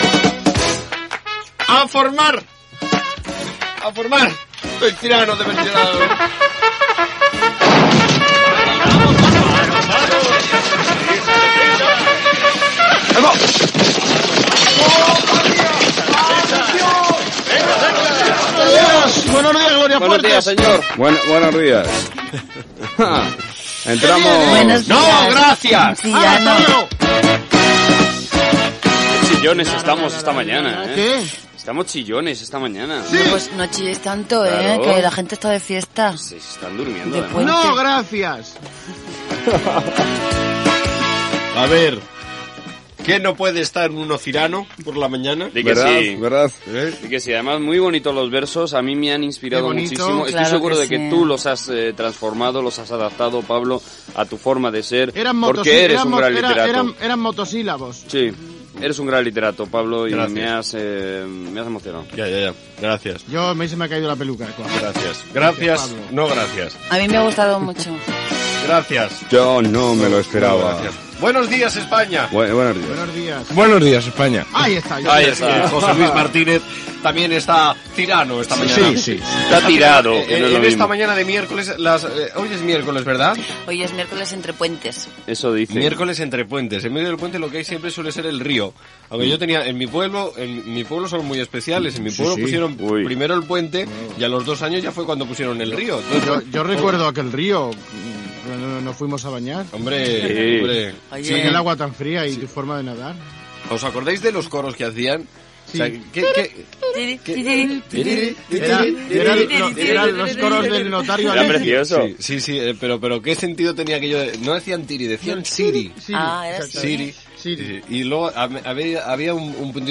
Diàleg de l'equip sobre el dia d'emissió, que està entre dos ponts, i els examens. Presentació i salutació de l'equip i tema musical
Entreteniment
FM